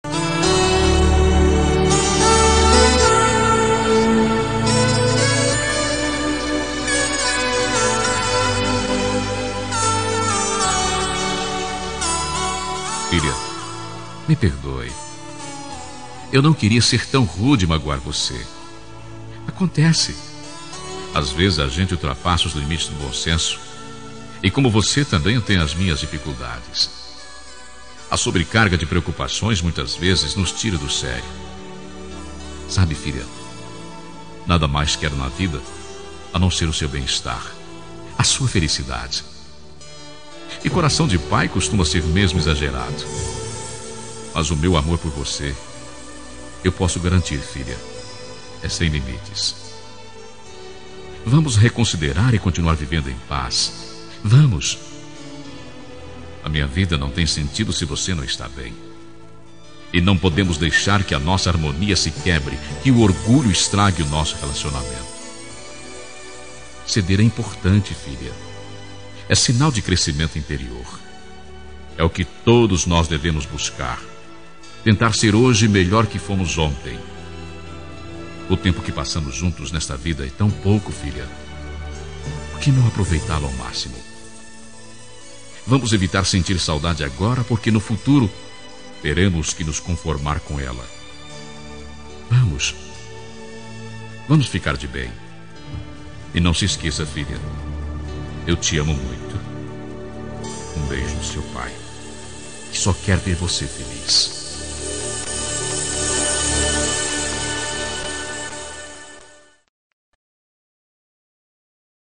Reconciliação Familiar – Voz Masculina – Cód: 088738 – Filha